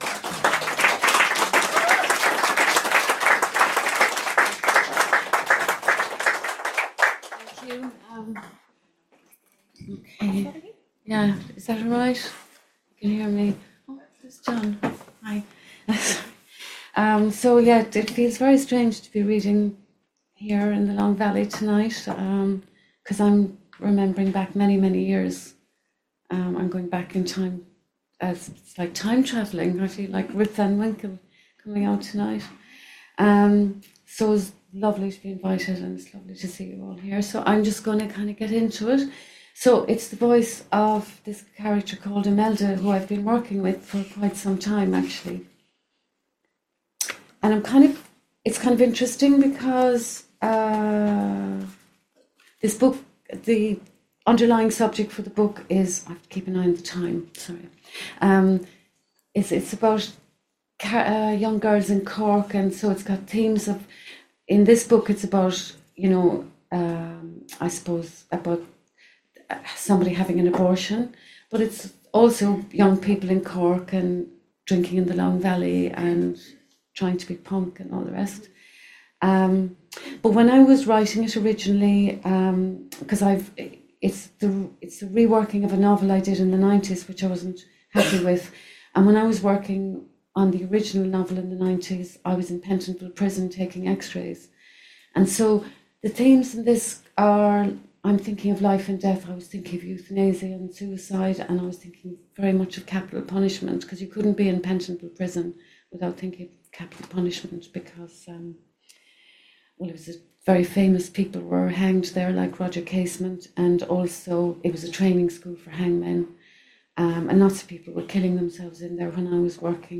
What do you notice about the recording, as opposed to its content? Ó Bhéal’s End of Year Event features